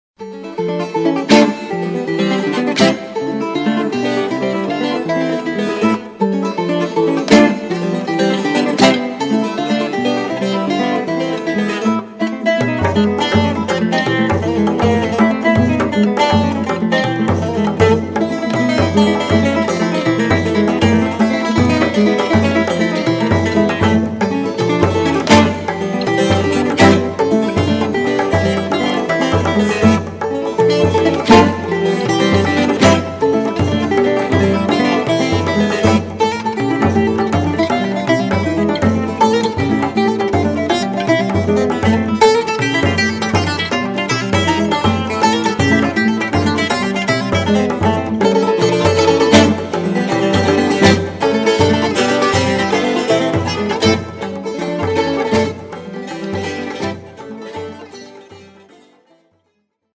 chitarra, chitarra battente, mandolino, mandola, percussioni